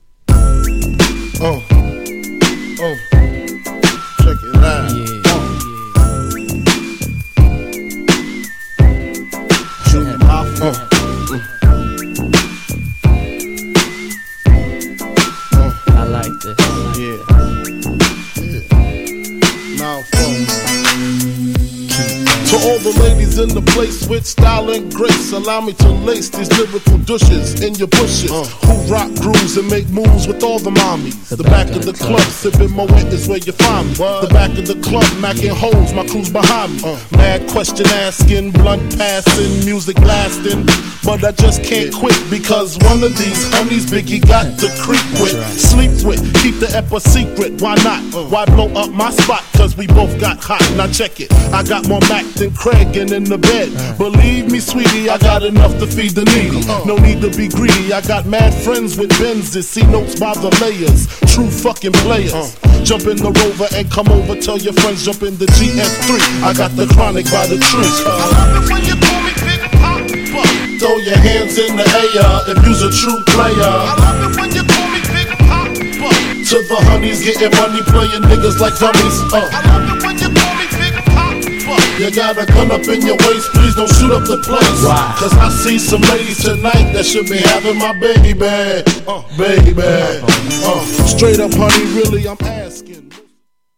GENRE Hip Hop
BPM 86〜90BPM
SMOOTHなフロウ # メロディアスなHIPHOP